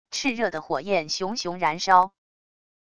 炽热的火焰熊熊燃烧wav音频